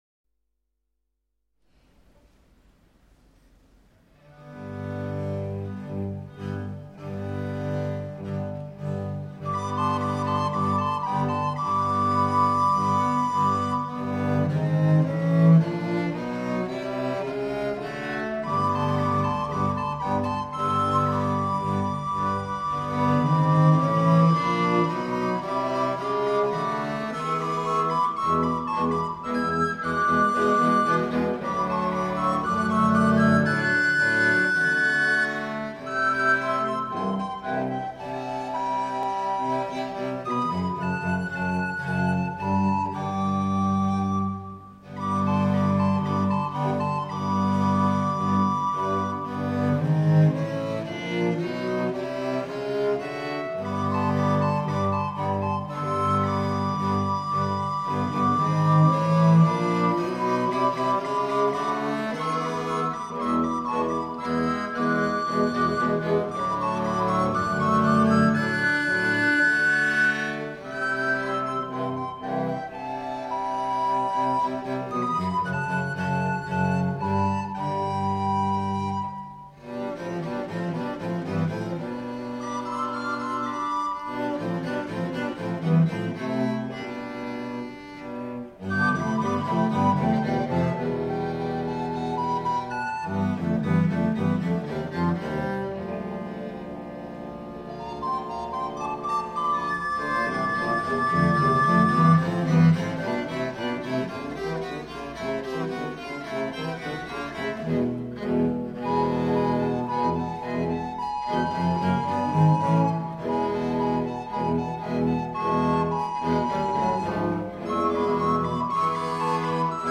It is scored for 2 recorders, tenor viol, bass viol and cello. The instrumentation was dictated by performers of the Pastores Ensemble. The recording is its premiere at the Brighton Festival on May 10th, 2000.
praeludium.mp3